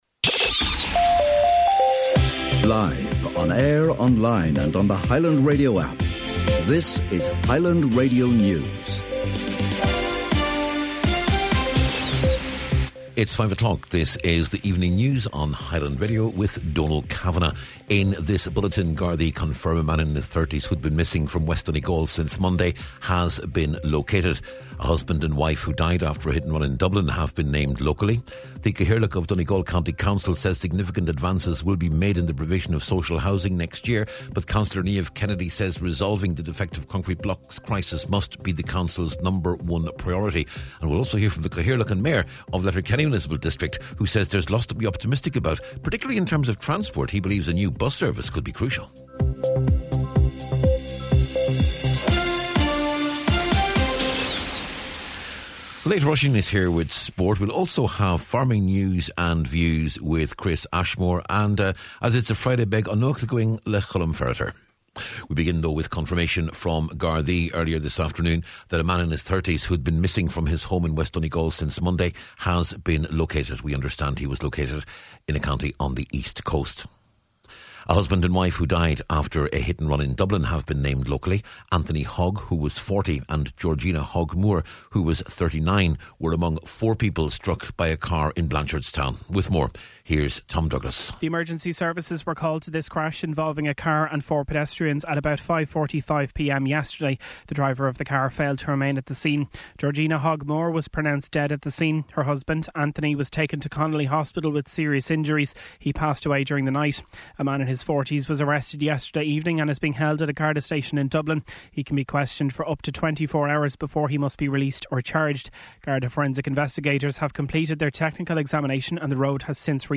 News, Nuacht, Sport and Farm News on Friday December 27th